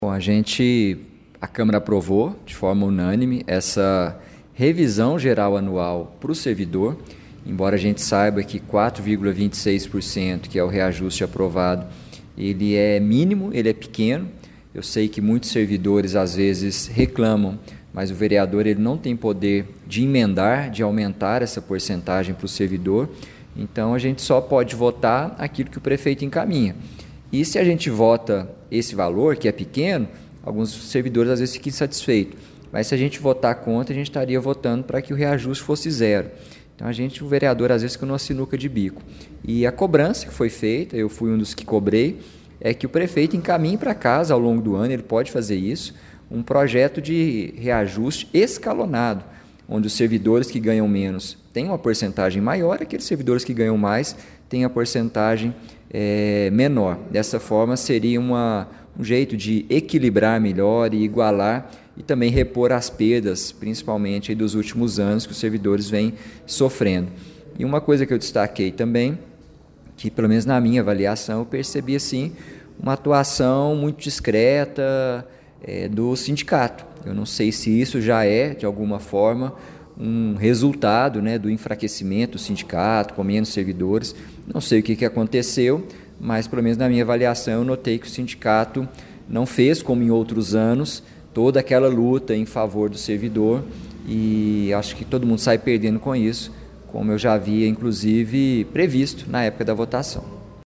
Durante o debate, o vereador Gustavo Henrique Duarte Silva (PSDB) ressaltou que, apesar de reconhecer que o índice de reajuste não atende plenamente às expectativas do funcionalismo, o Legislativo não tem prerrogativa para alterar o percentual enviado pelo Executivo.